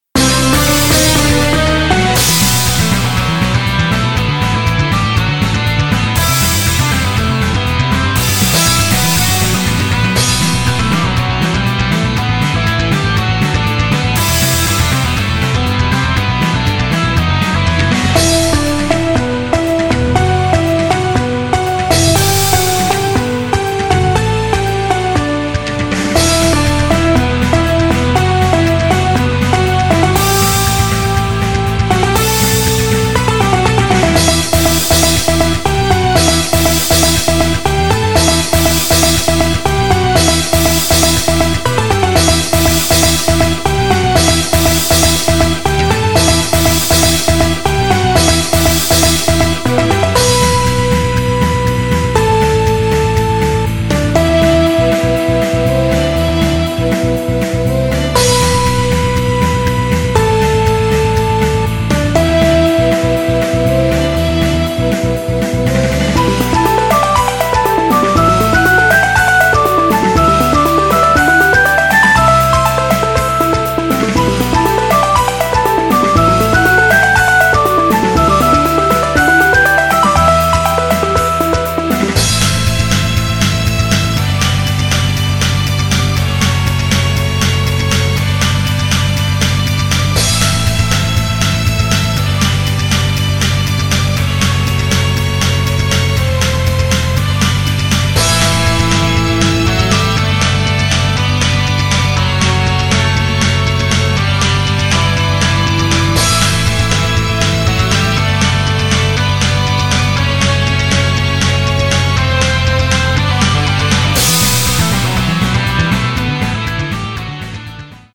～～戦闘系の曲～～
（モノ）